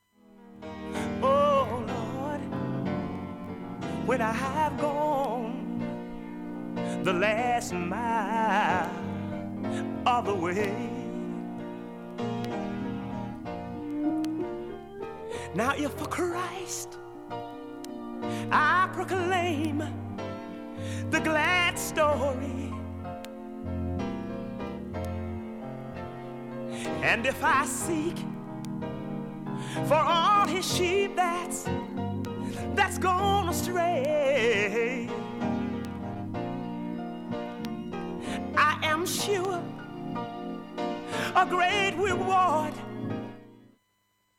音質もきれいです。
瑕疵部分 B-4中盤に見えにくい2ミリの薄いスレで 静かな部ですがかすかなプツが20回出ます。
モダンソウル名盤